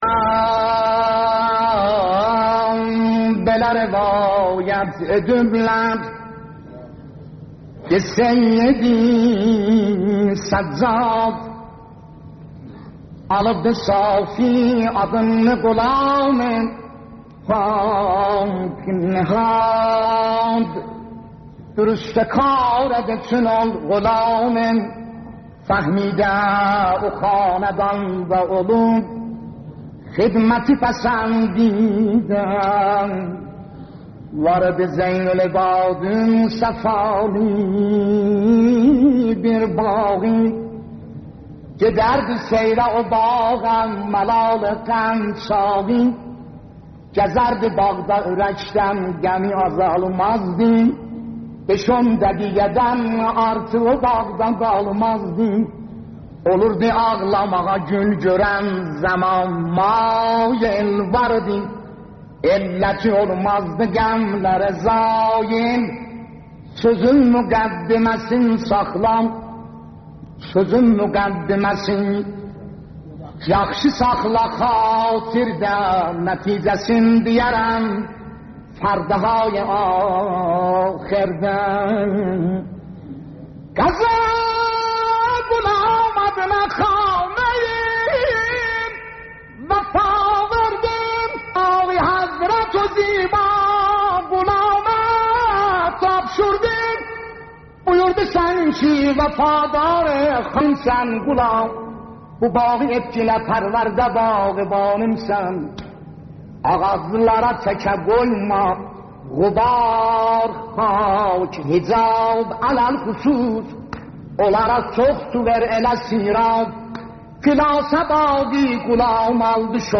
روضه ی امام سجاد